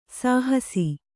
♪ sāhasi